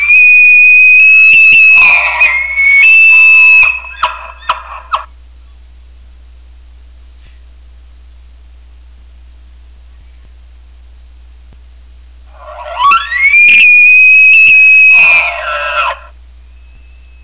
Just for grins and giggles. . . Bull Elk
elk.wav